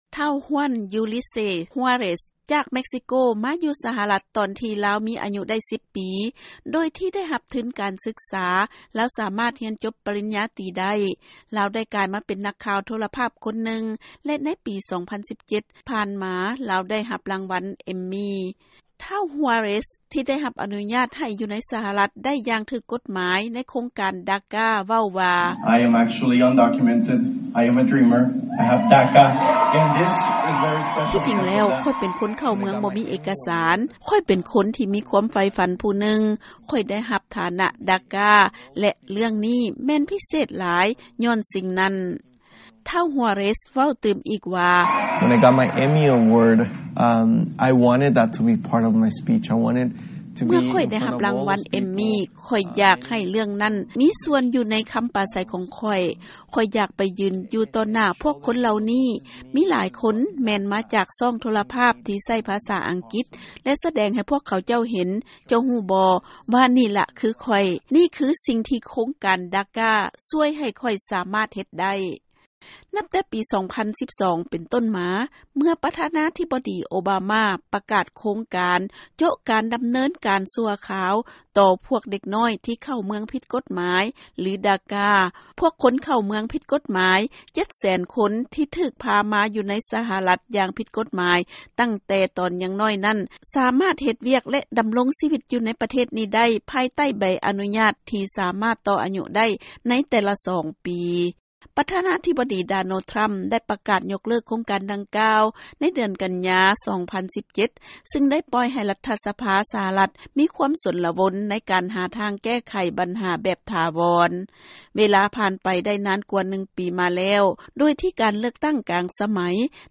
ເຊີນຟັງລາຍງານເລື້ອງພວກຢູ່ໃນໂຄງການດາກາ ຄອງຄອຍໃຫ້ການເລືອກຕັ້ງກາງສະໄໝ ສ້າງໃຫ້ມີການປ່ຽນແປງ